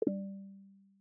error-I0kj0UAl.mp3